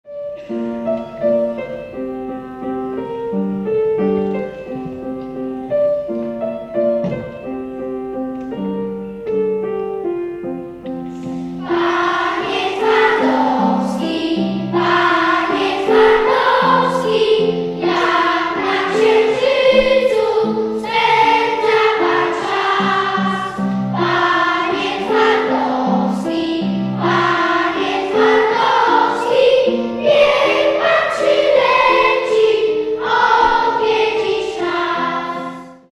wykonuje chór ch³opiêcy kandydatów do chóru